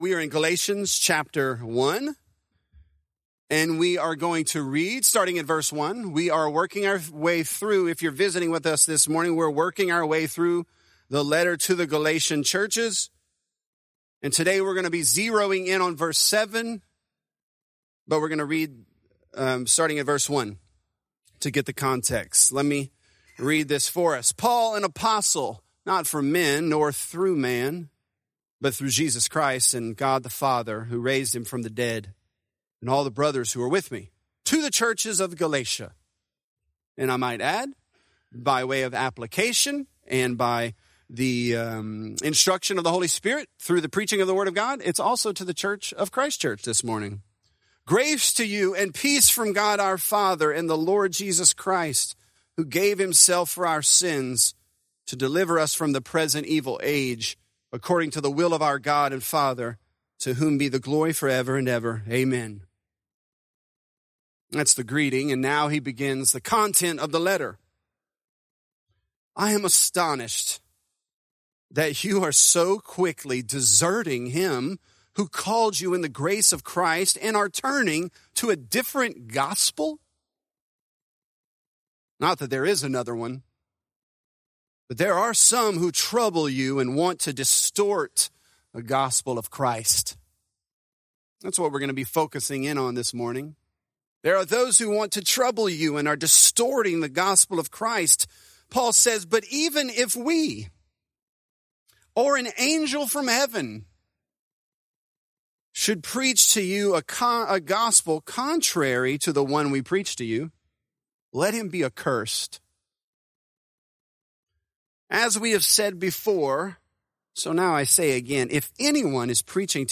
Galatians: An Adjusted Gospel | Lafayette - Sermon (Galatians 1)